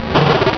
Cri de Malosse dans Pokémon Rubis et Saphir.